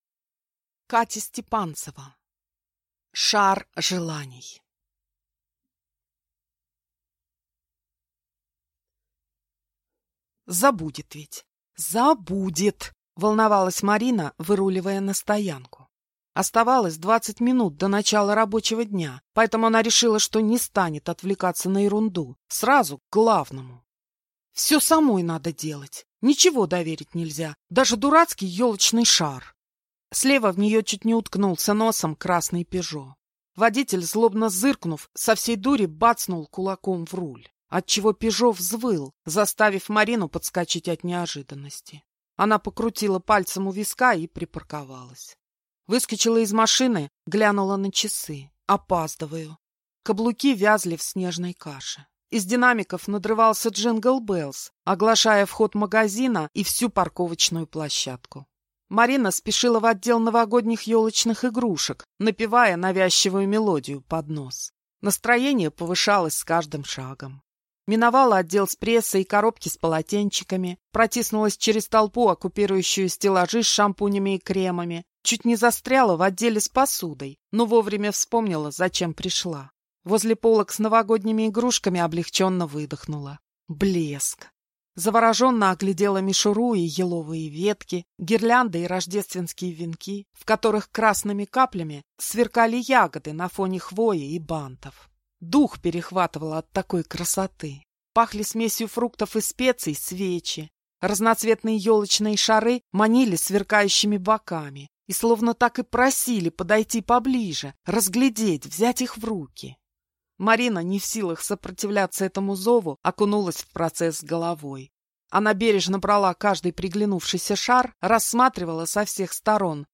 Аудиокнига Шар желаний | Библиотека аудиокниг
Прослушать и бесплатно скачать фрагмент аудиокниги